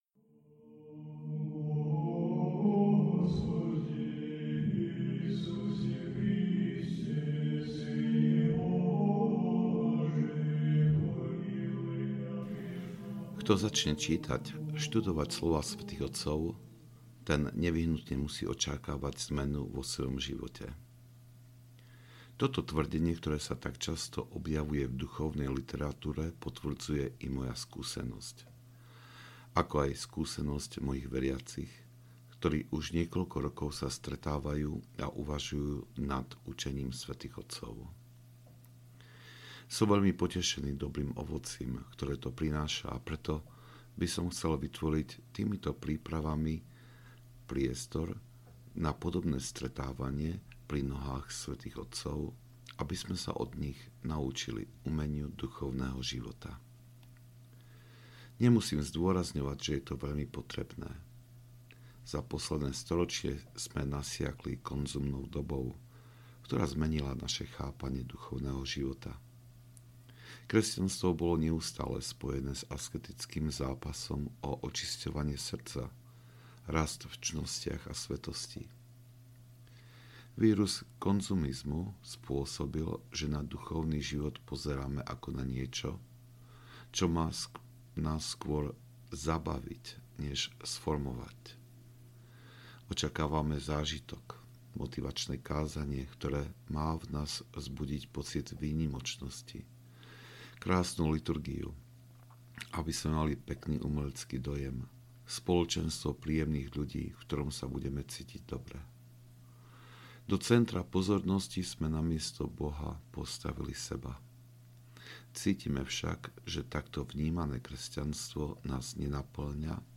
Nad slovami sv. Izáka Sýrskeho - Homília 1 – 4 audiokniha
Ukázka z knihy